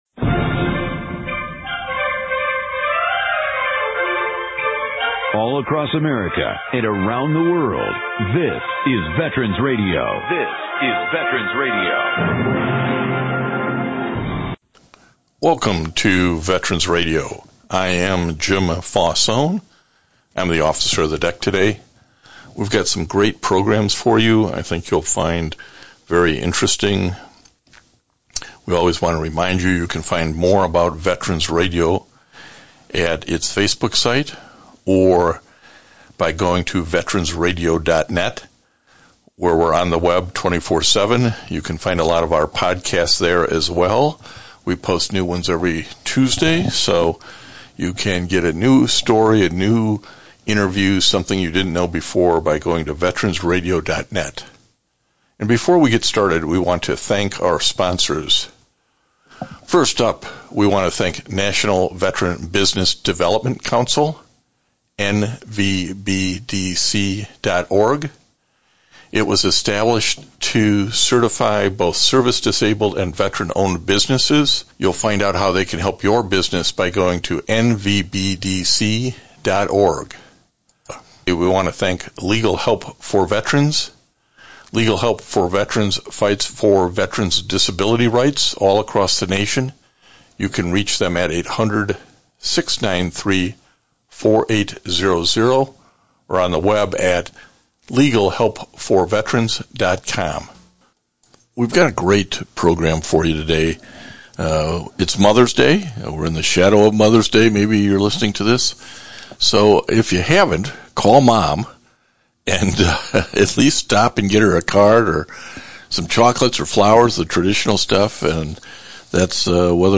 Imagine being an active duty military service moms with a young family. You will hear from two moms who are active duty with kids.